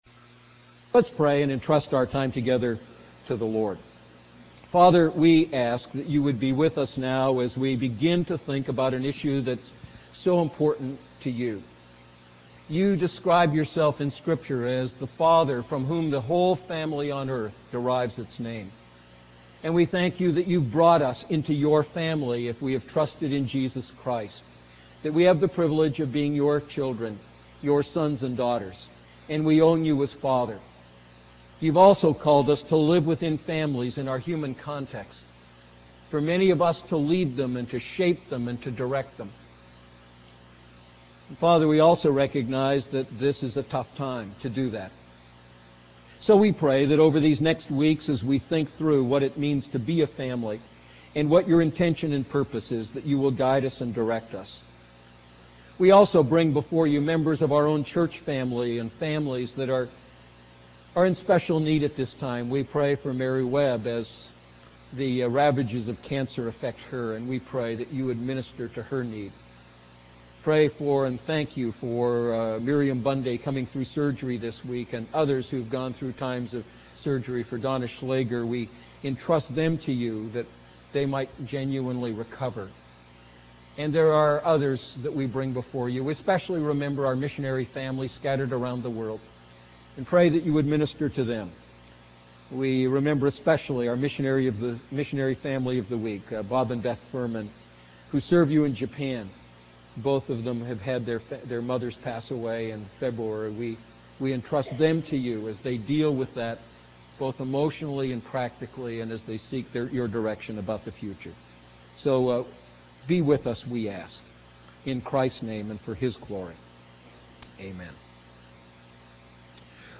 A message from the series "Family Matters."